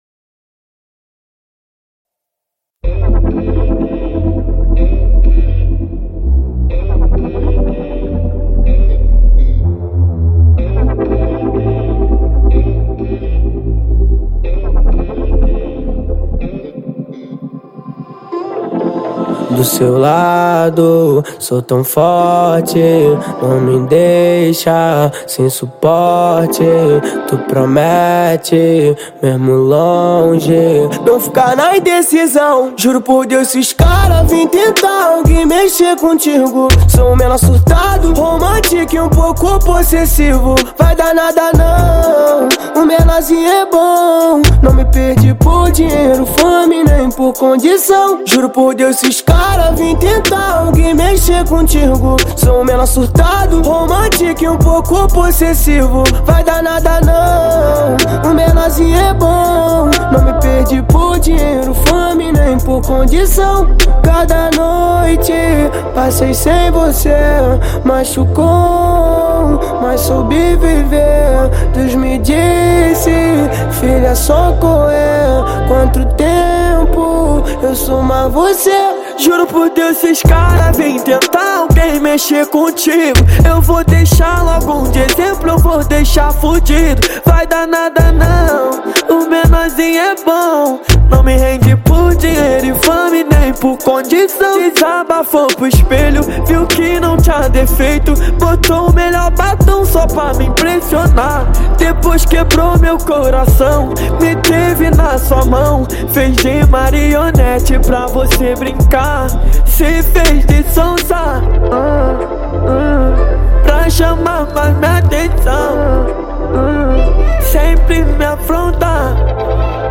2024-11-22 09:01:05 Gênero: Trap Views